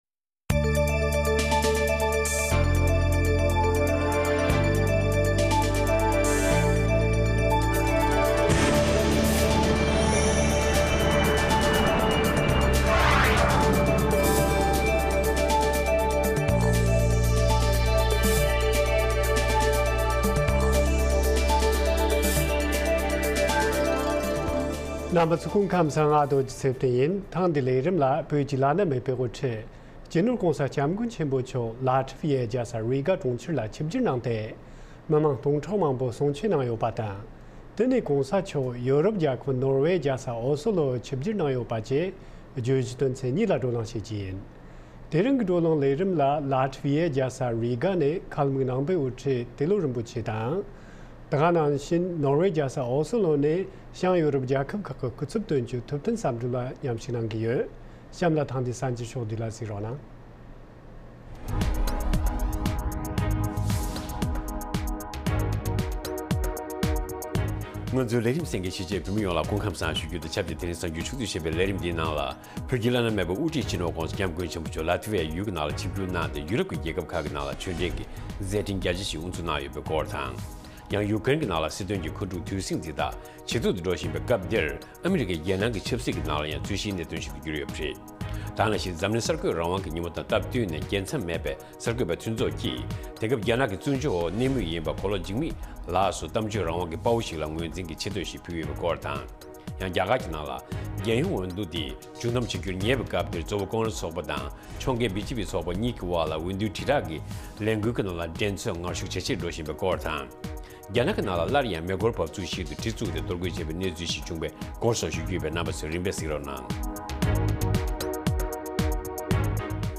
དགོང་དྲོའི་གསར་འགྱུར ཉིན་ལྟར་ཐོན་བཞིན་པའི་བོད་དང་ཨ་རིའི་གསར་འགྱུར་ཁག་དང་། འཛམ་གླིང་གསར་འགྱུར་ཁག་རྒྱང་སྲིང་ཞུས་པ་ཕུད། དེ་མིན་དམིགས་བསལ་ལེ་ཚན་ཁག་ཅིག་རྒྱང་སྲིང་ཞུ་བཞིན་ཡོད། རྒྱང་སྲིང་དུས་ཚོད། Daily བོད་ཀྱི་དུས་ཚོད། 22:00 འཛམ་གླིང་གཅིག་གྱུར་གྱི་དུས་ཚོད། 1400 ལེ་ཚན་རིང་ཐུང་། 60 གསན་ན། MP༣ Podcast